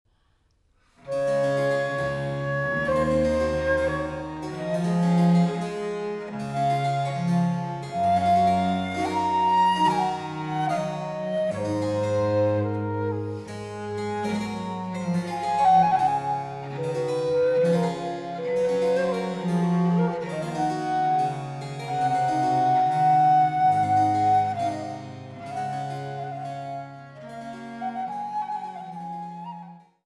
Gayment